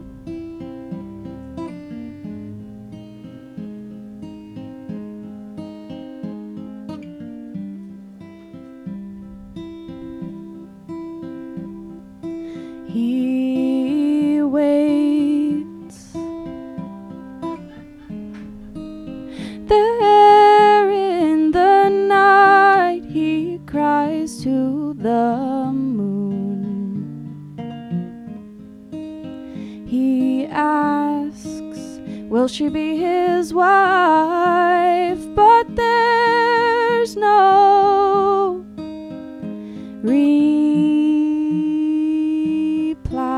singer-songwriter
performs live on the WGXC Afternoon Show